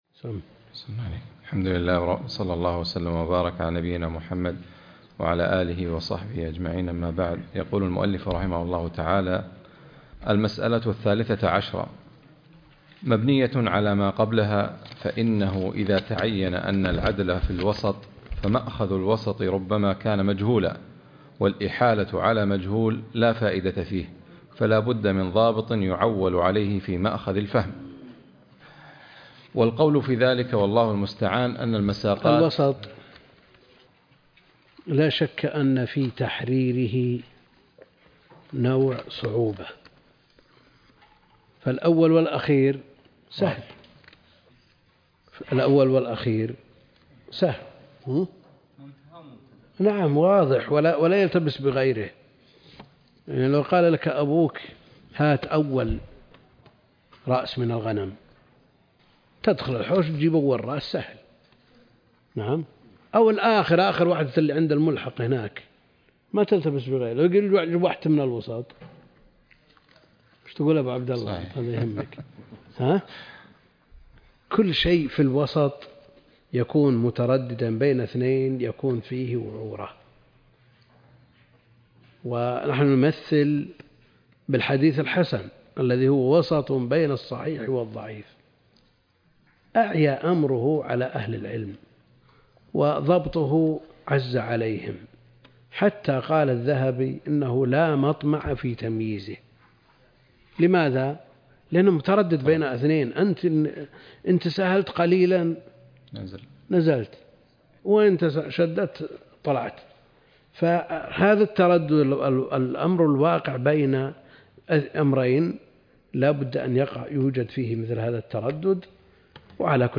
عنوان المادة الدرس ( 128) التعليق على الموافقات تاريخ التحميل الأربعاء 14 ديسمبر 2022 مـ حجم المادة 18.29 ميجا بايت عدد الزيارات 207 زيارة عدد مرات الحفظ 115 مرة إستماع المادة حفظ المادة اضف تعليقك أرسل لصديق